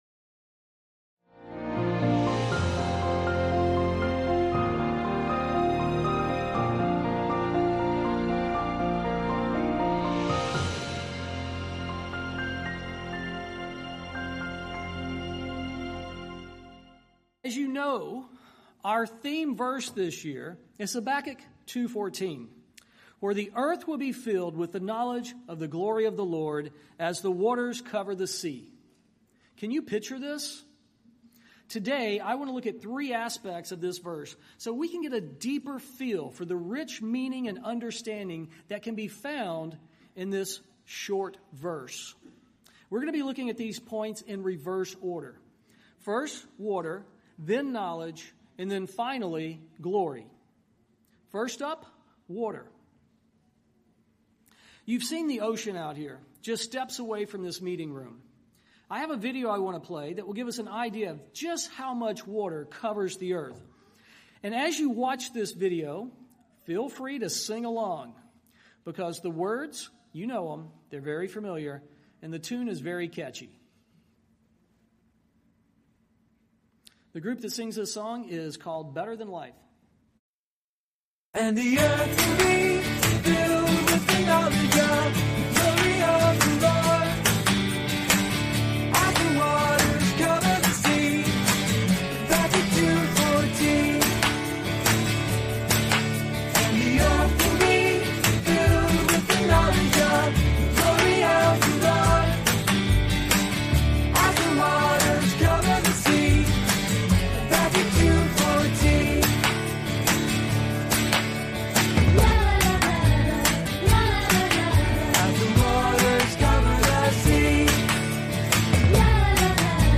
This sermon was given at the Montego Bay, Jamaica 2020 Feast site.